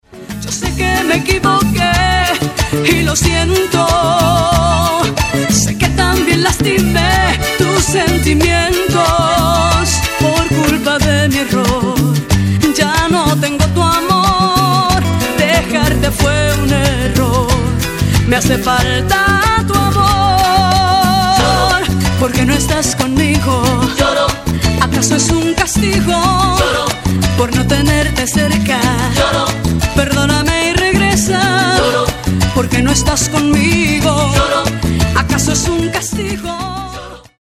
romantic and passionate songs